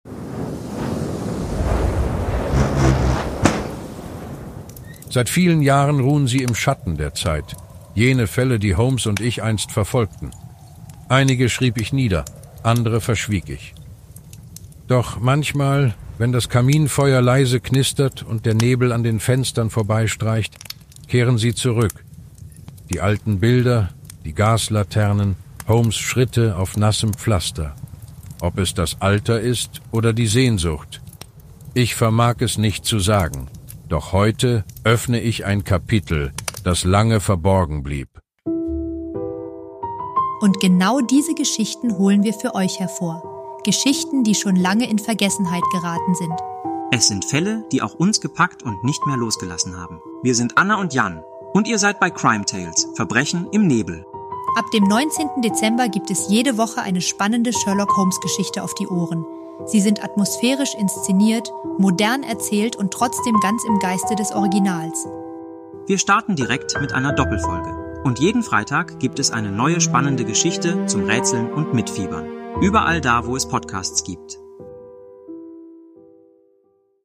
Trailer
Sherlock Holmes Hörspiel